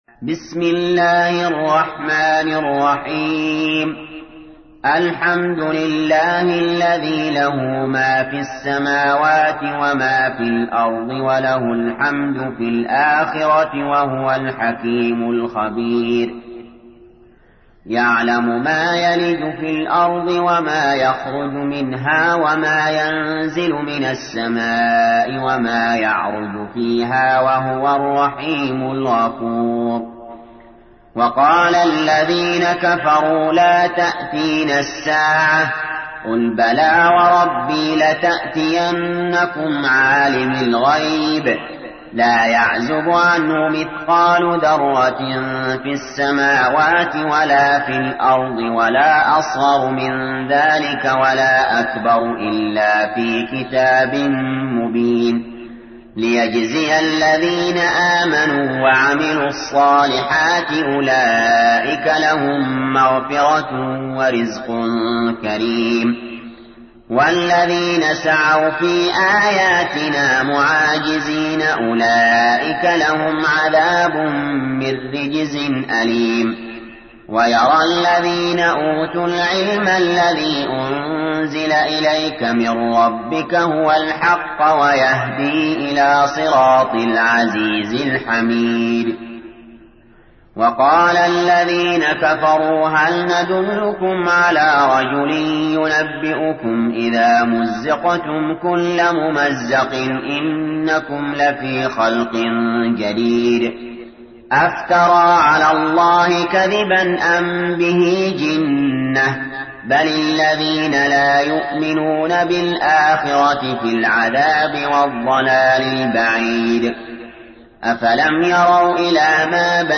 تحميل : 34. سورة سبأ / القارئ علي جابر / القرآن الكريم / موقع يا حسين